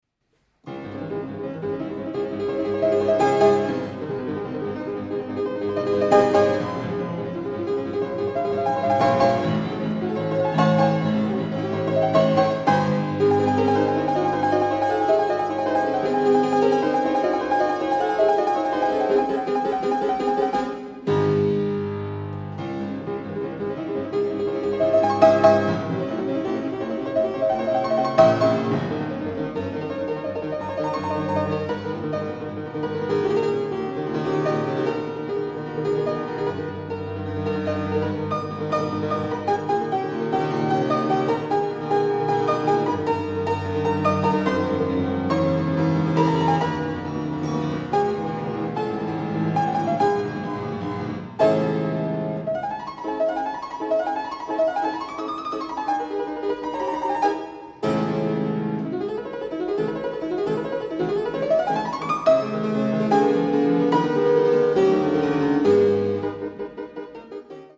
Sonate cis-Moll op. 27
Presto agitato